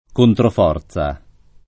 controforza [ kontrof 0 r Z a ] s. f. (fis.)